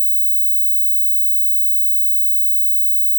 Sound Buttons: Sound Buttons View : Pitido